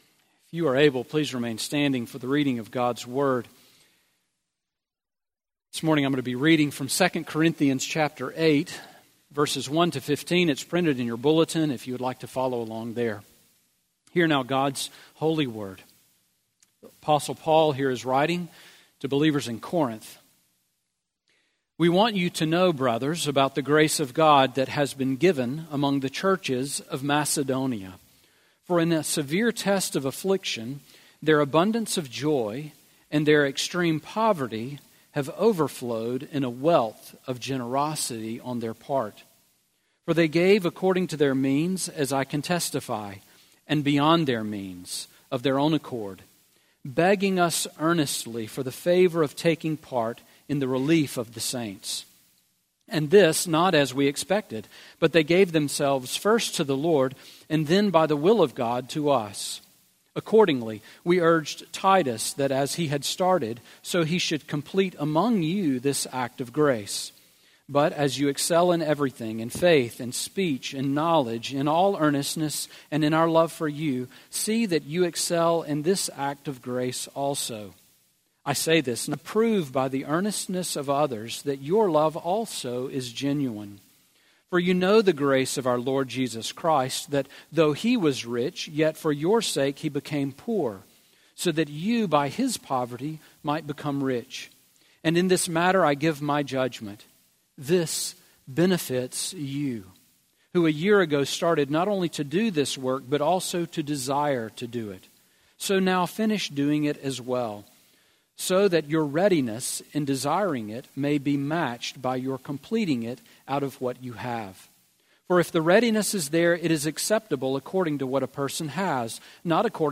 Sermon Audio from Sunday
Sermon on 2 Corinthians 8:1-15 from January 13